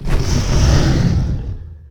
combat / creatures / dragon / he / attack1.ogg